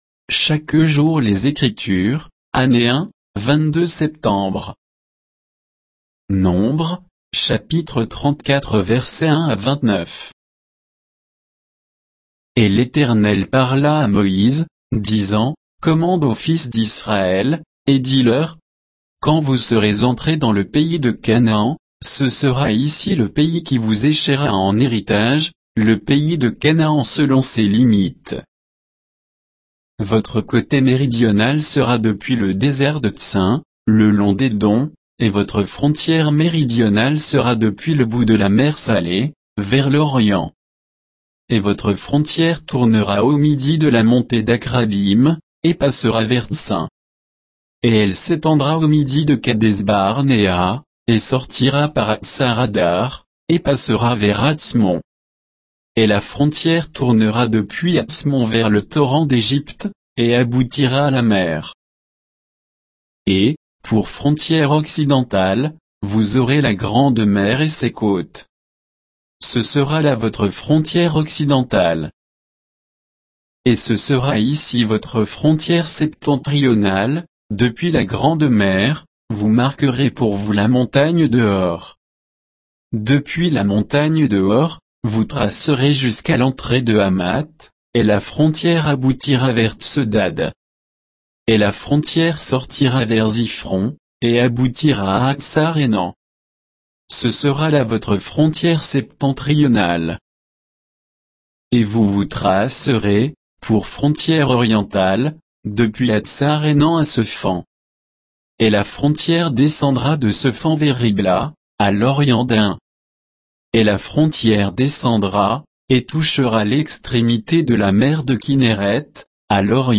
Méditation quoditienne de Chaque jour les Écritures sur Nombres 34